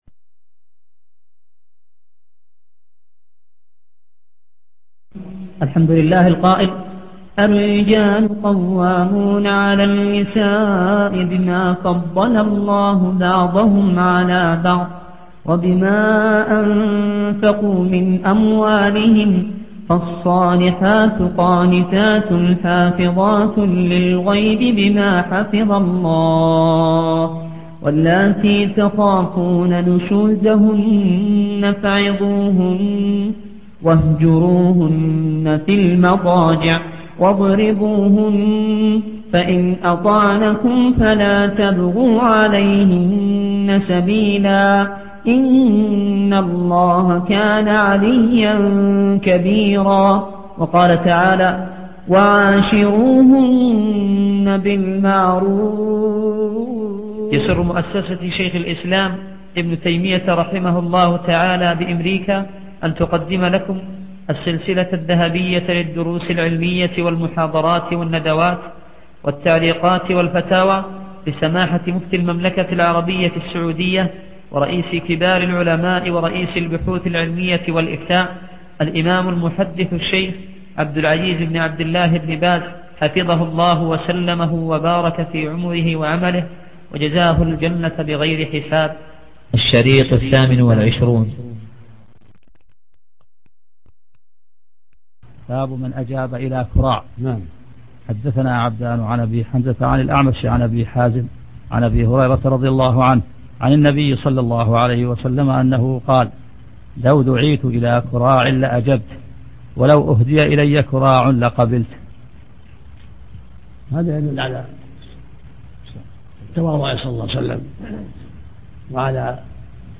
من سلسلة المرأة المسلمة الشيخ عبد العزيز بن عبد الله بن باز الدرس 4